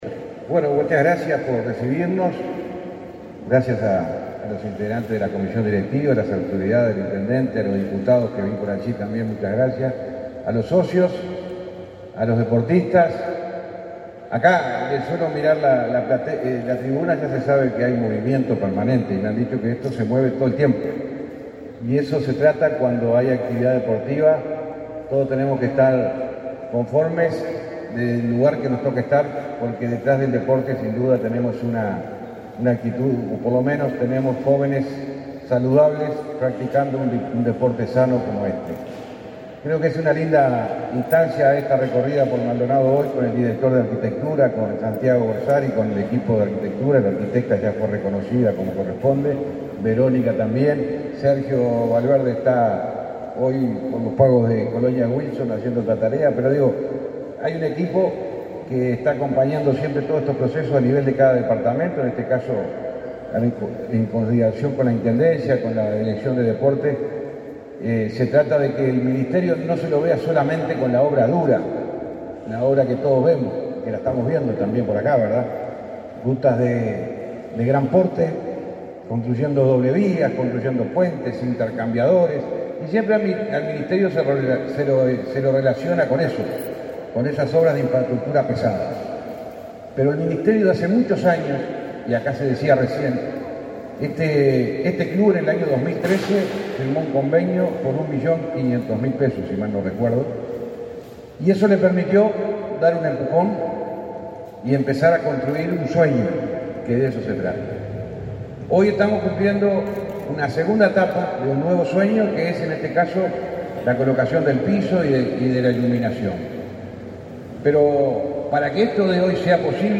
Palabras del ministro del MTOP, José Luis Falero
Falero acto.mp3